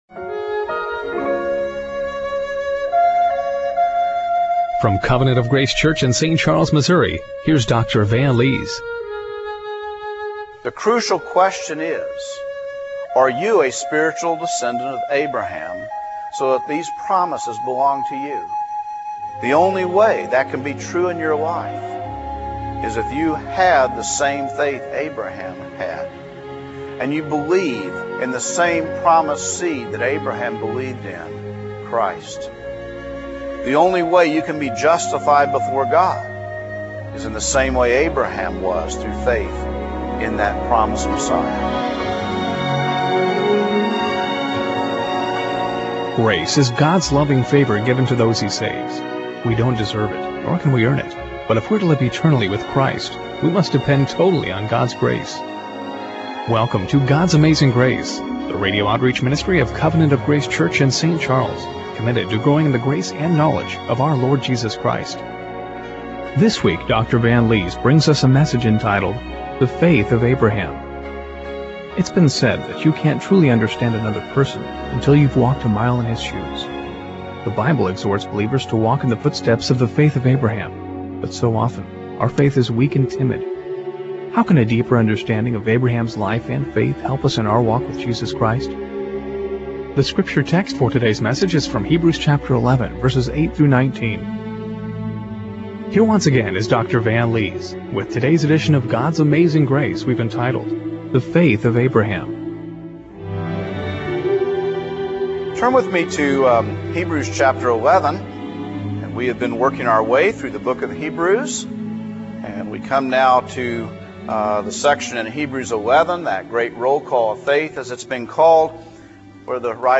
Hebrews 11:8-19 Service Type: Radio Broadcast How can a deeper understanding of Abraham's life and faith help us in our walk with Jesus Christ?